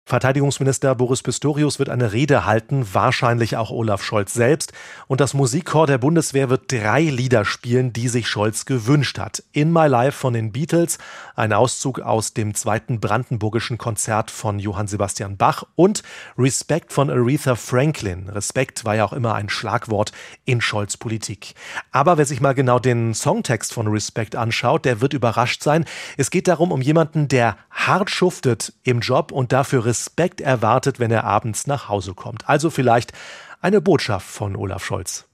Nachrichten So läuft der Große Zapfenstreich für Olaf Scholz